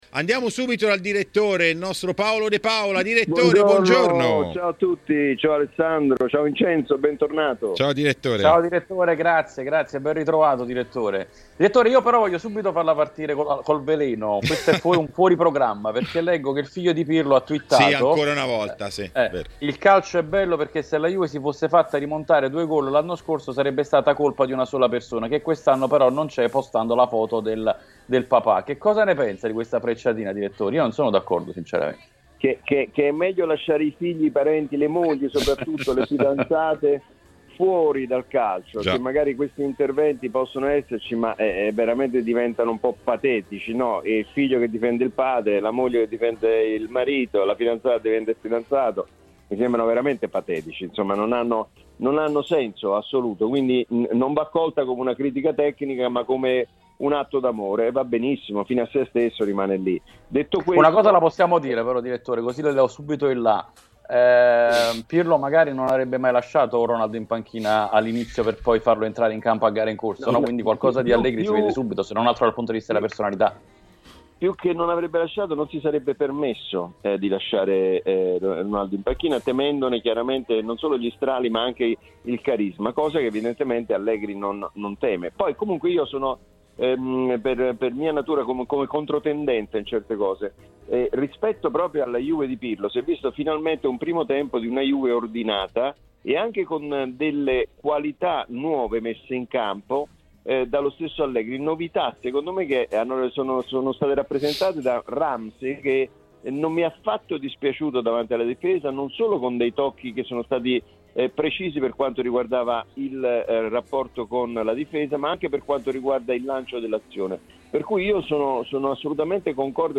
in diretta nell'editoriale di TMW Radio.